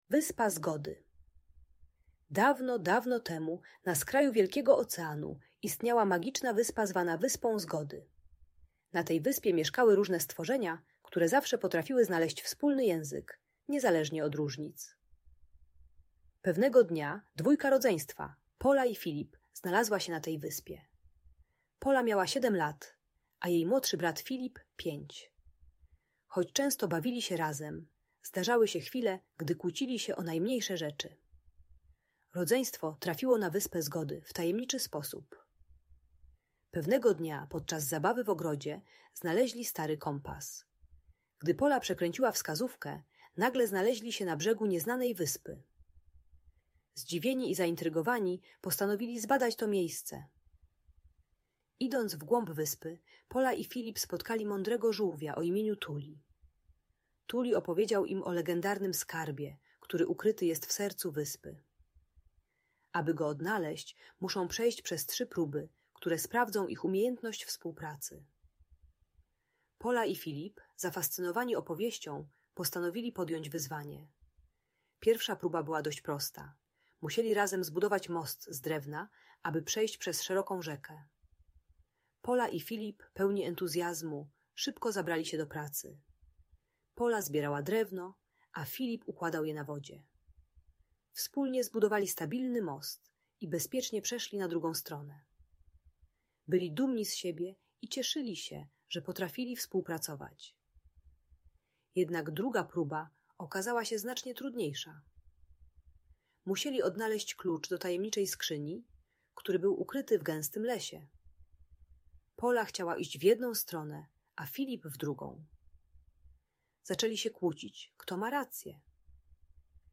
Opowieść o Wyspie Zgody - Rodzeństwo | Audiobajka